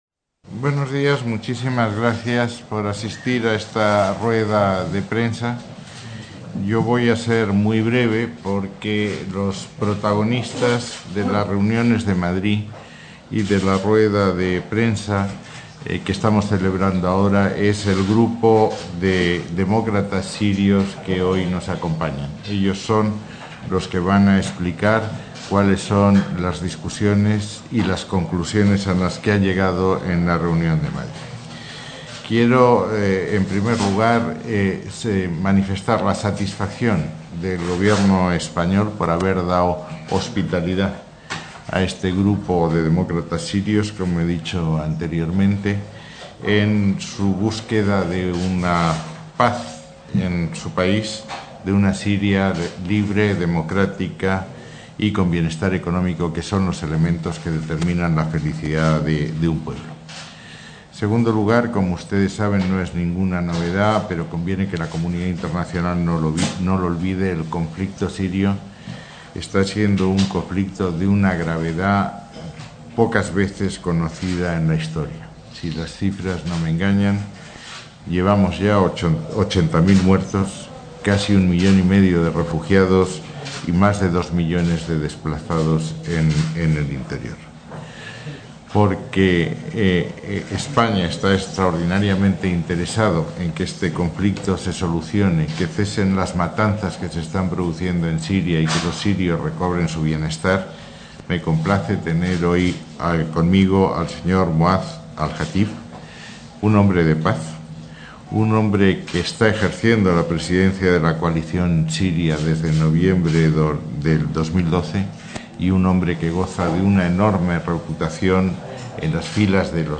Rueda de prensa del ministro de Asuntos Exteriores y de Cooperación y Al Jatib